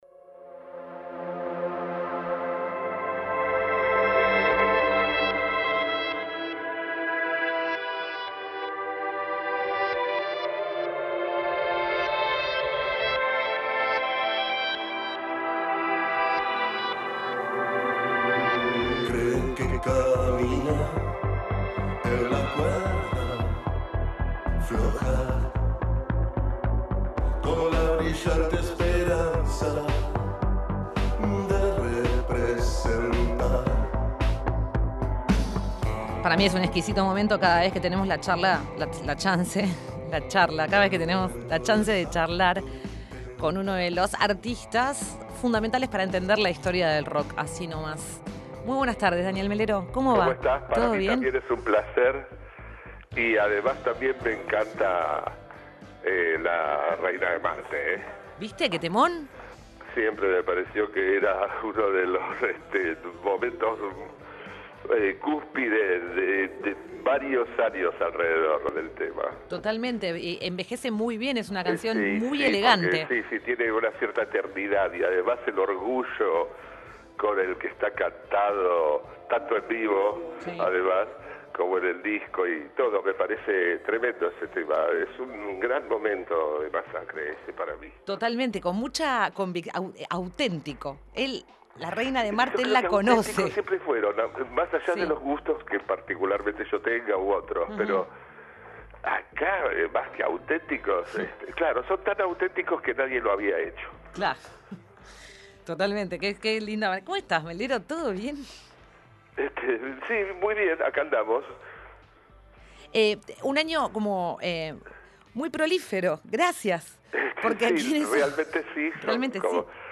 Entrevista-Daniel-Melero-EDIT.mp3